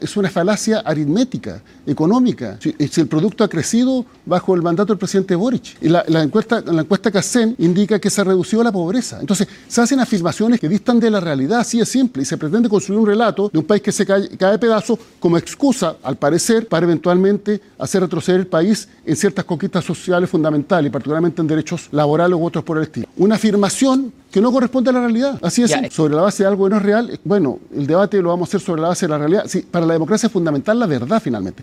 En una entrevista con CNN Chile Radio, el ministro del Interior, Álvaro Elizalde, respondió con dureza a los cuestionamientos del equipo económico del presidente electo José Antonio Kast sobre el déficit fiscal y la situación en Gendarmería.